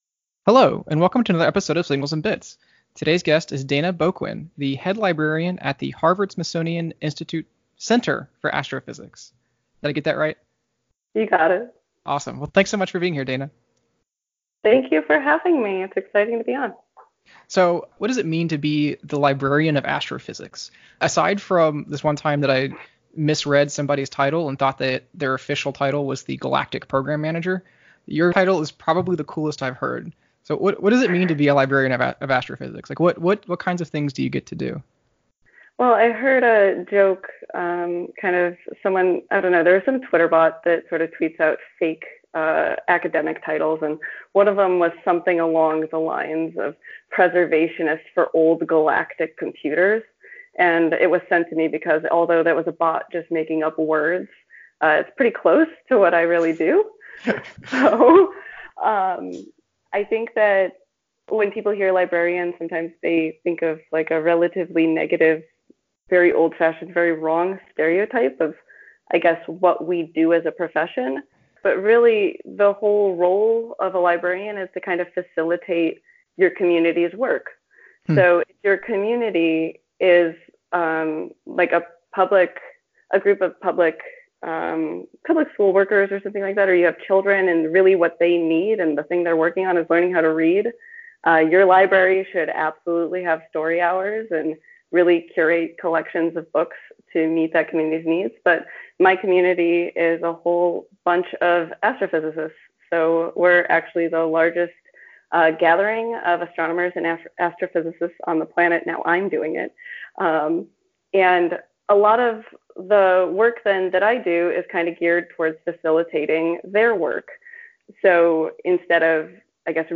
Play Rate Listened List Bookmark Get this podcast via API From The Podcast Interviews with amazing people about software radio, the technology behind it, and everything it makes possible.